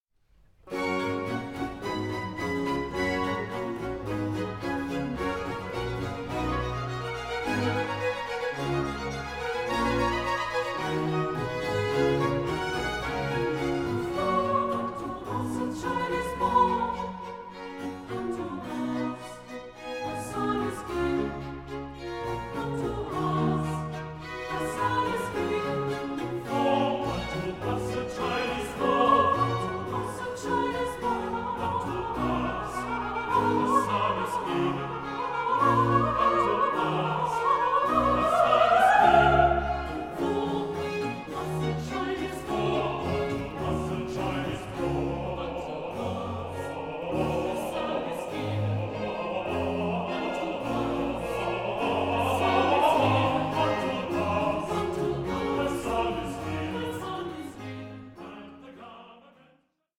Celebrating Vocal Music
Chorus